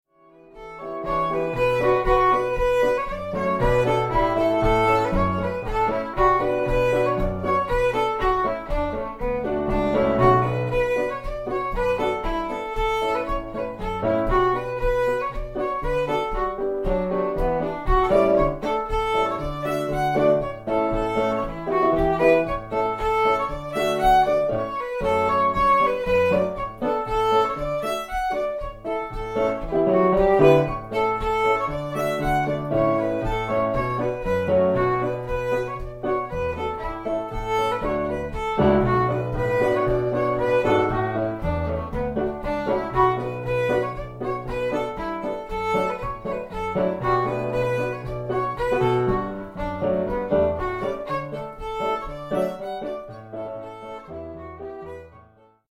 Contra Dance
(trad. reels)